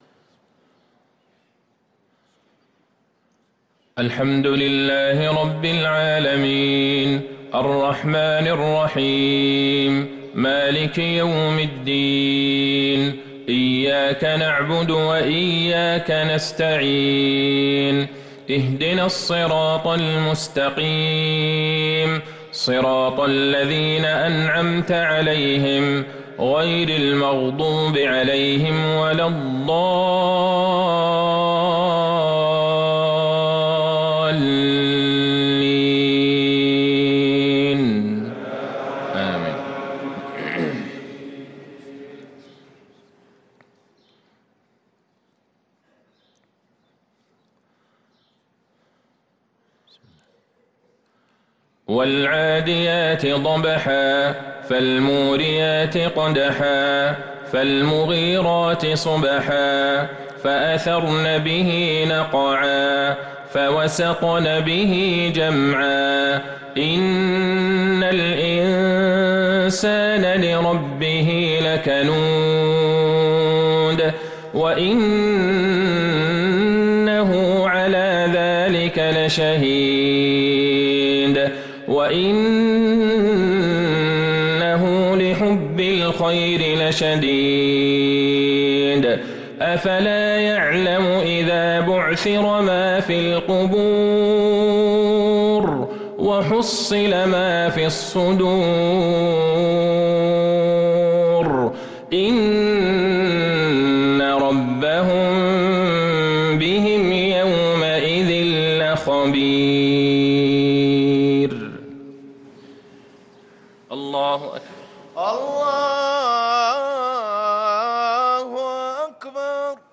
صلاة المغرب للقارئ عبدالله البعيجان 2 جمادي الآخر 1445 هـ
تِلَاوَات الْحَرَمَيْن .